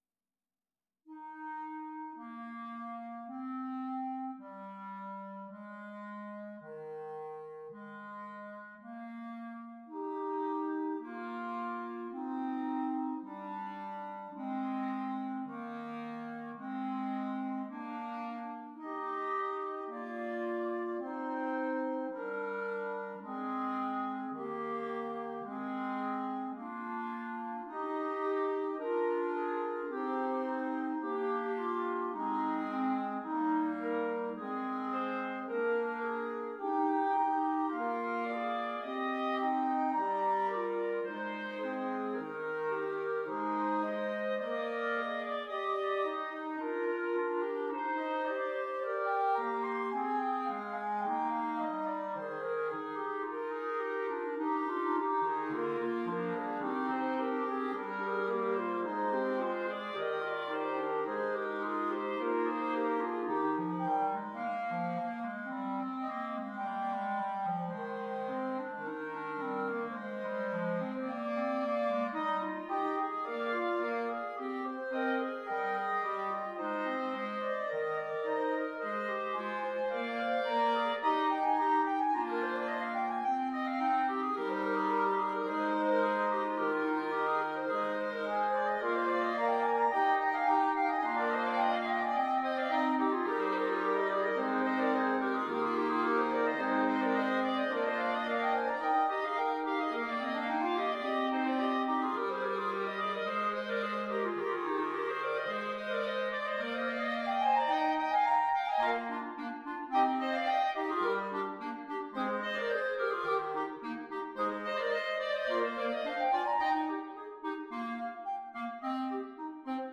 5 Clarinets